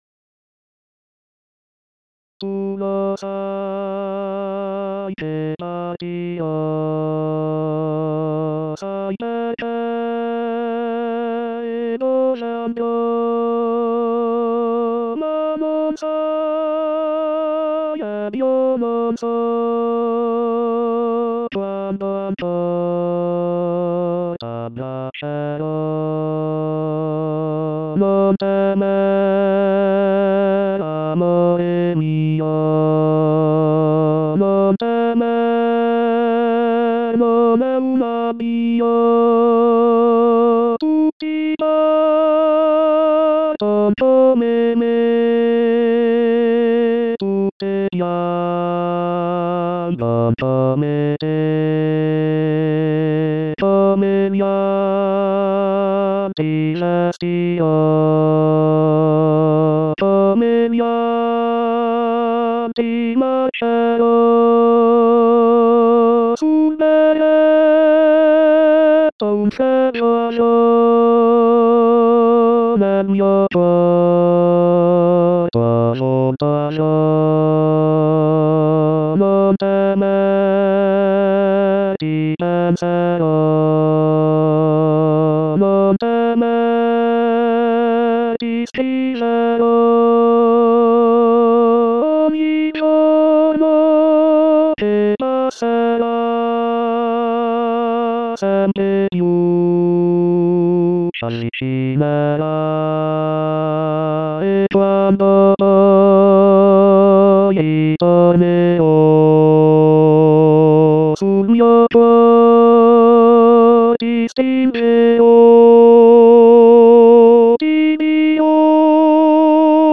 La Ballata del soldato tenor 2.mp3